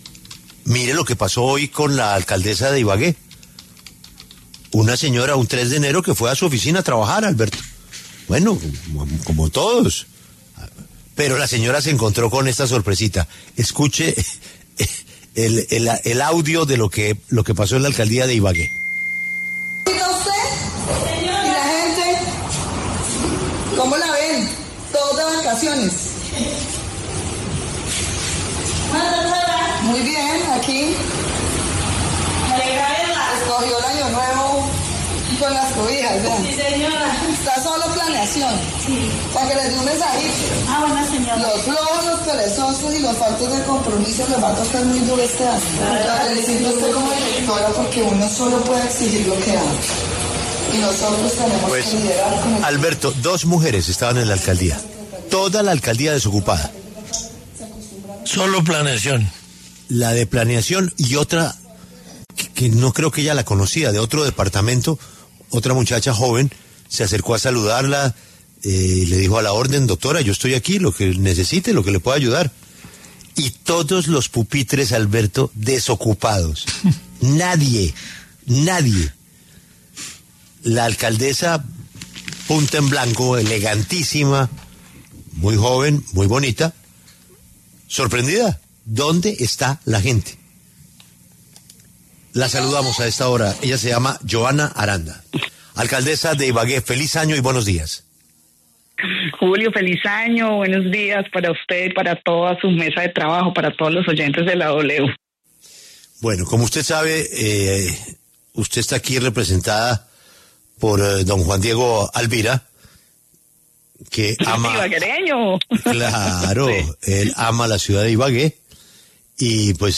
Johana Aranda, alcaldesa de Ibagué, habló en La W sobre su llegada el pasado 2 de enero a las instalaciones de la administración municipal, cuando no encontró a casi nadie trabajando.